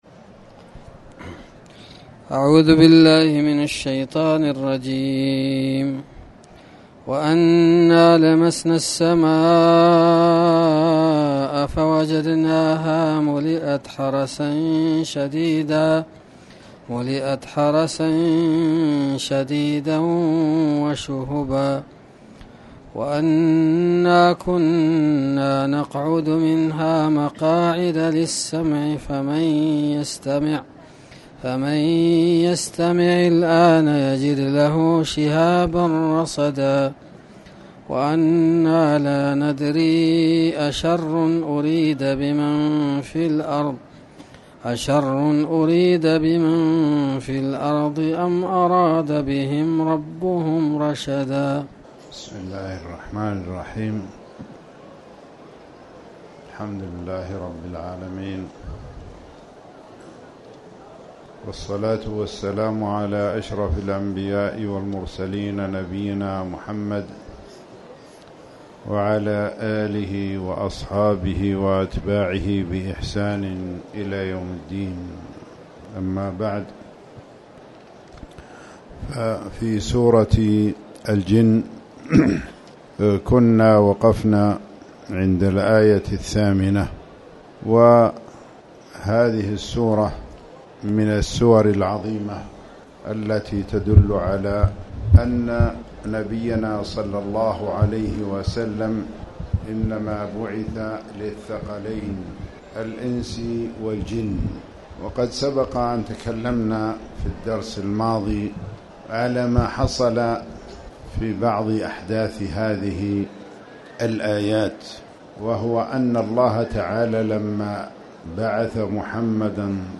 تاريخ النشر ٧ شعبان ١٤٣٩ هـ المكان: المسجد الحرام الشيخ